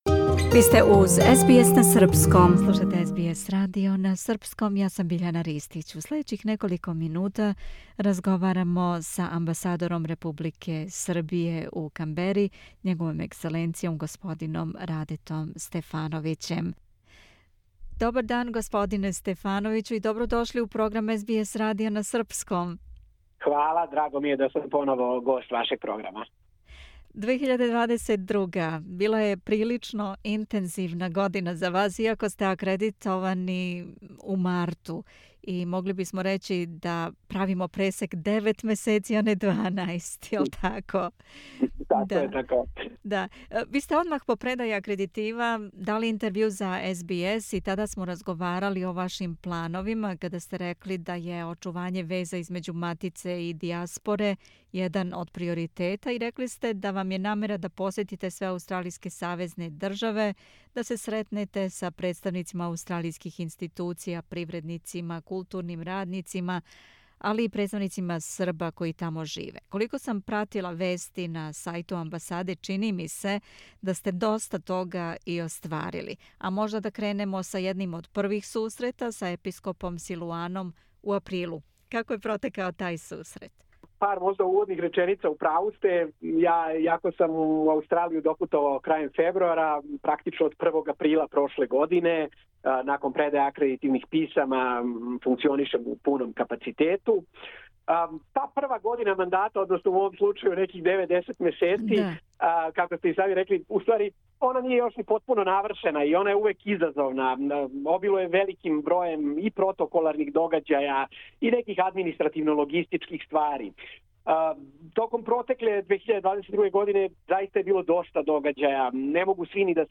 У ексклузивном интервјуу за СБС на срспком амбасадор Србије у Канбери Њ.Е. господин Раде Стефановић осврнуо се на протеклих девеt месеци откако је предао акредитиве и званично постао дипломатски прeдставник Србије у Аустралији. Каже да је задовољан постигнутим резултатима али да увек тежи вишим циљевима.
interview-ambasador.mp3